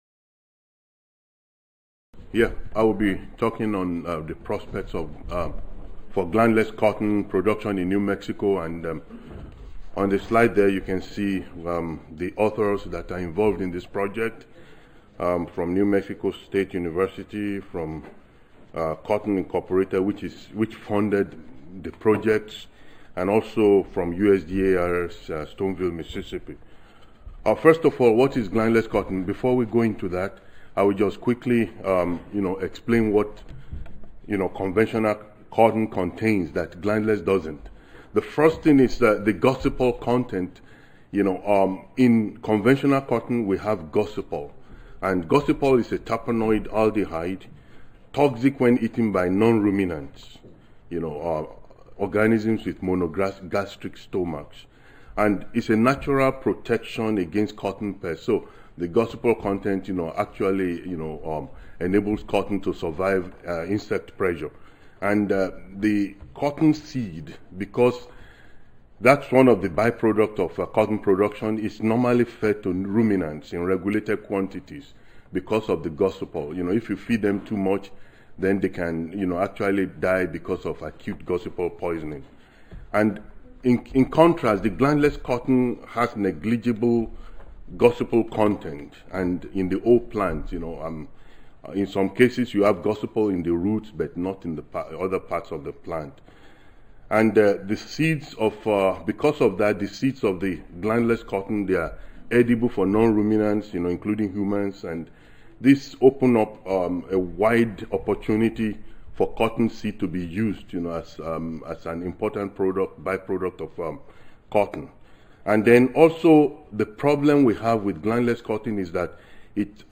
Session: Cotton Agronomy & Physiology - Wednesday Early Morning - Session B (2014 Beltwide Cotton Conferences (January 6-8, 2014))
Audio File Recorded Presentation